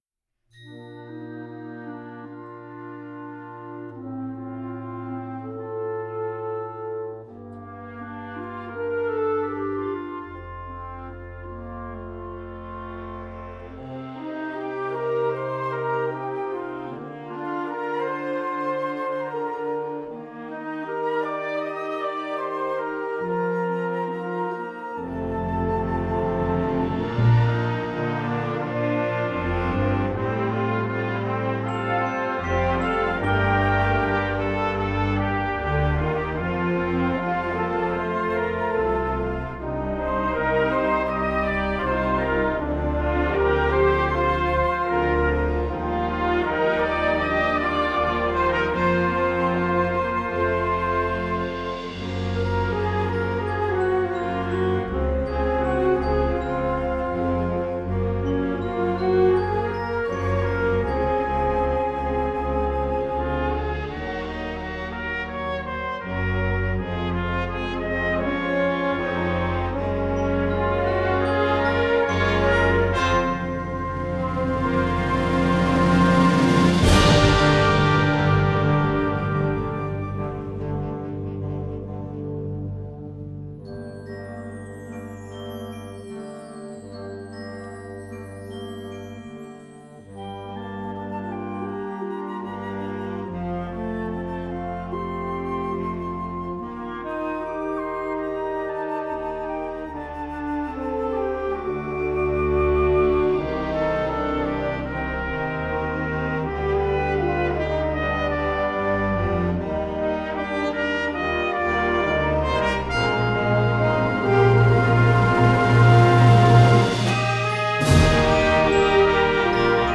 Gattung: Konzertwerk für Jugendblasorchester
Besetzung: Blasorchester
Geschrieben mit fließenden Melodien und üppigen Harmonien.